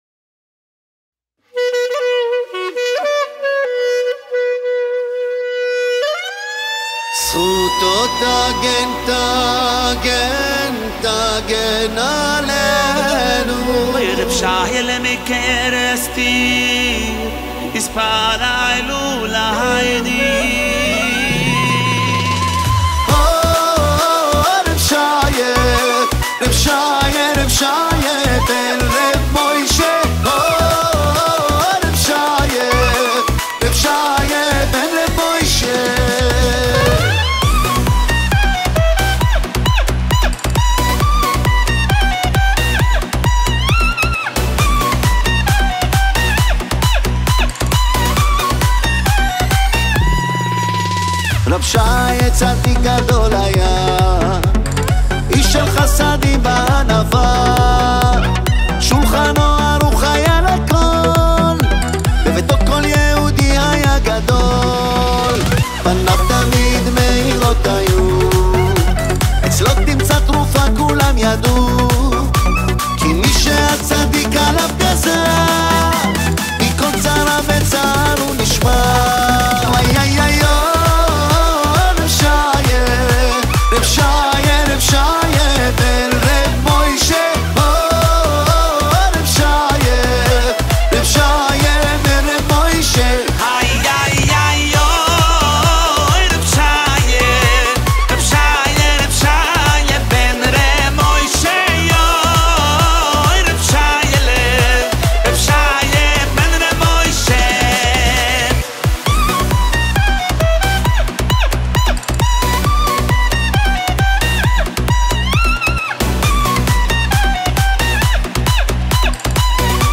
גיטרות
בס